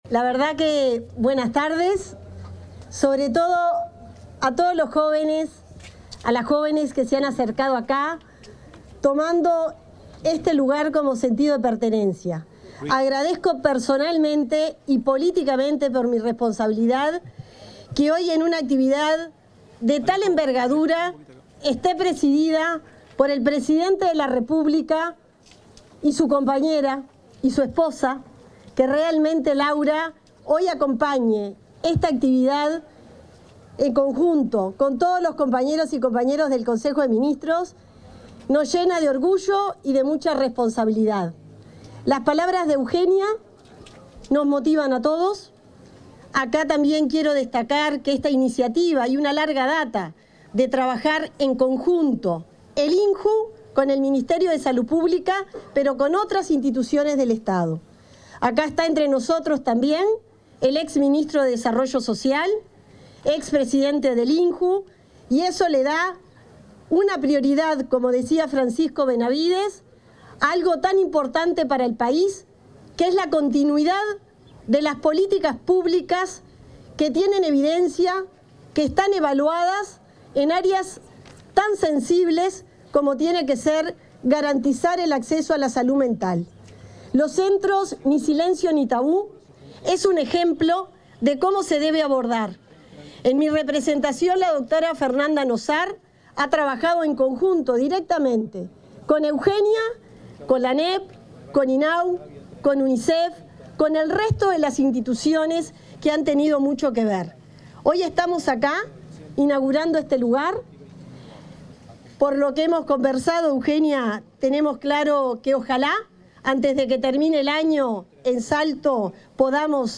Palabras de autoridades en inauguración de centro Ni Silencio Ni Tabú
La ministra de Salud Pública, Cristina Lustemberg, y su par de Desarrollo Social, Gonzalo Civila, se expresaron en el acto de inauguración de un